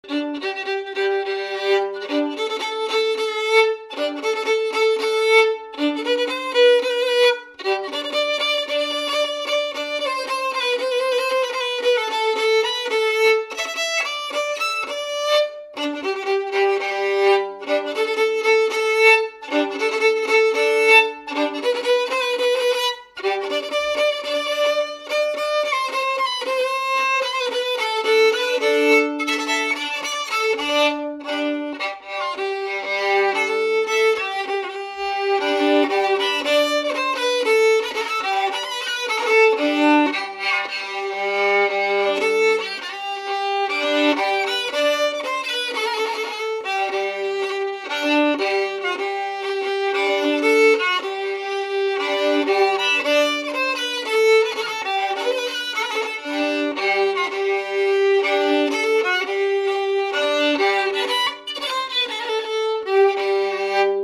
Résumé instrumental
gestuel : à marcher
circonstance : fiançaille, noce
Pièce musicale inédite